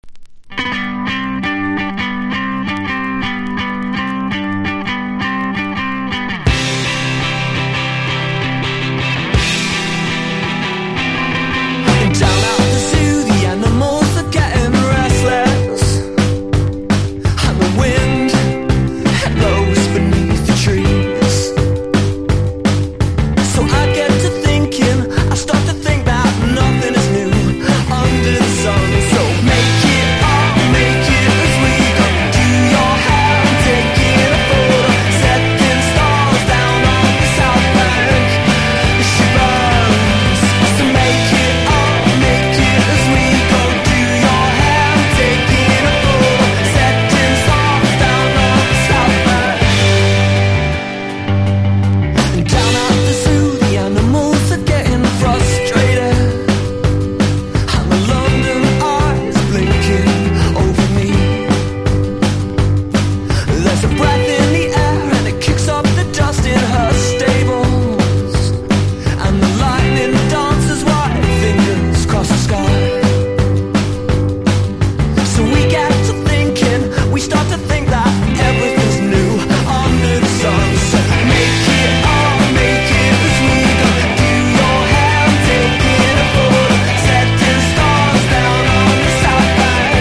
1. 00S ROCK >